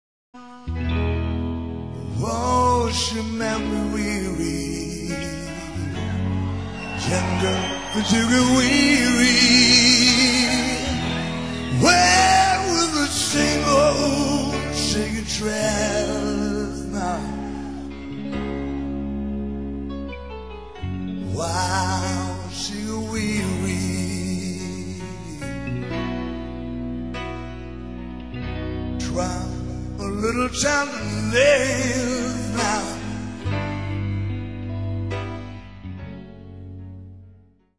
CD-LIVE